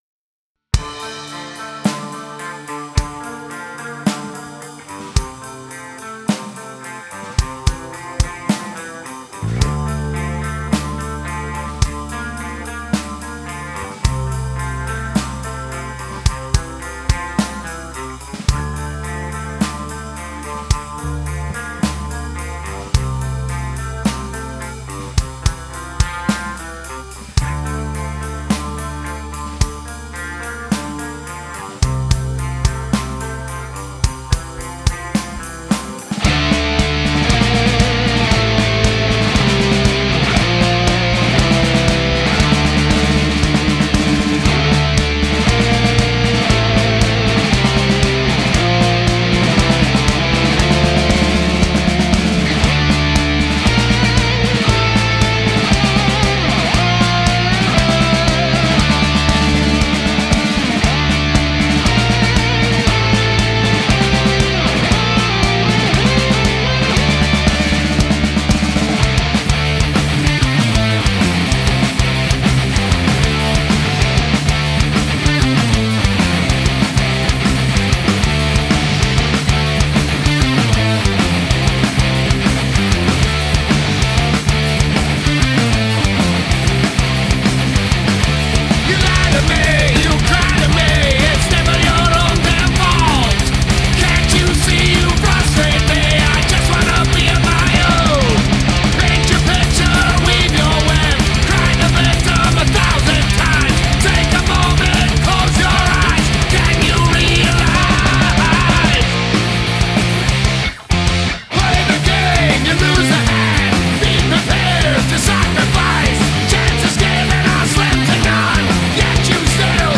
Ein musikalischer Schlag in die Fresse.